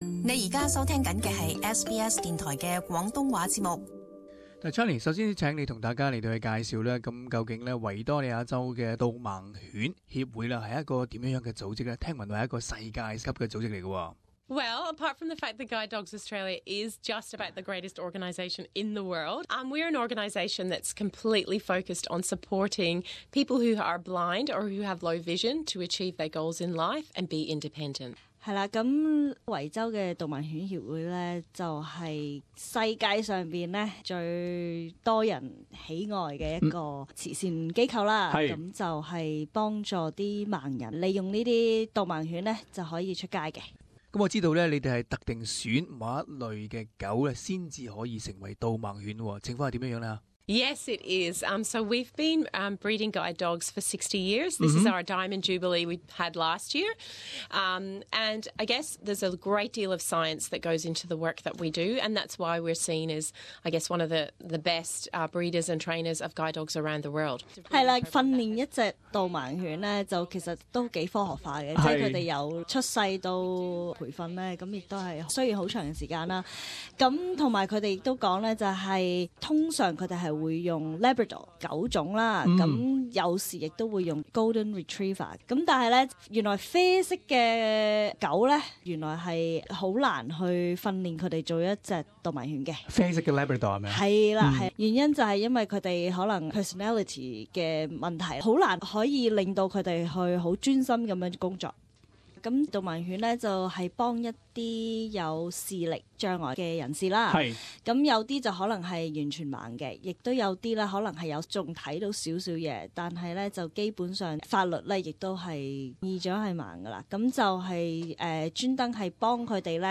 【社團專訪】導盲犬如何幫助失明人士